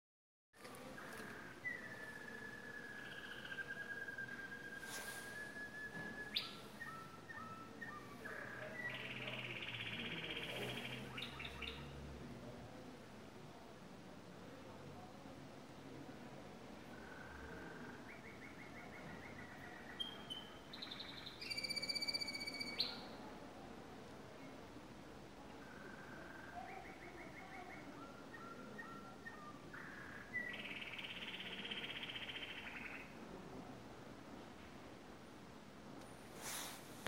Es wird an den Pflanzen geknabbert, gesungen, was das Zeug hält (unter Impressionen kann man sich unseren Kanarienhahn Pauli anhören) und natürlich viel geflogen.